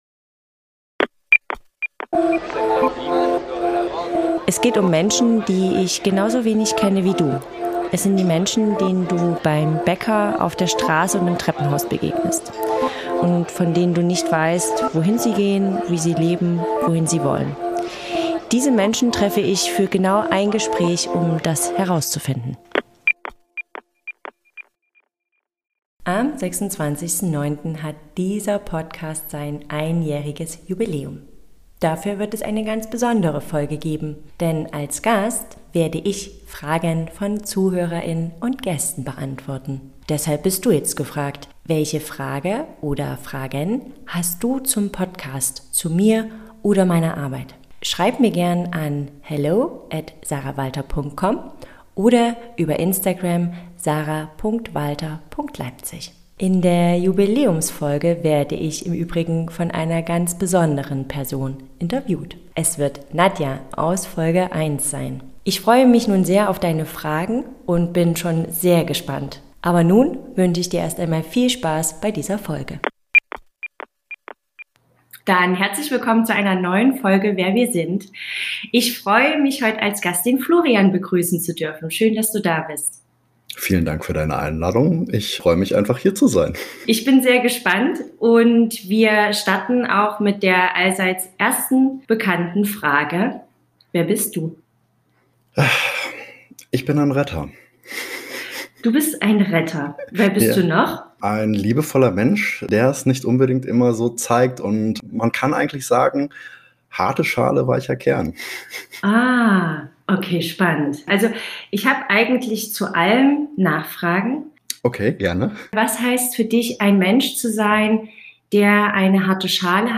- ein Gespräch. ohne Skript. ohne Labels.